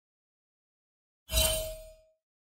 league-of-legends-question-mark-ping.mp3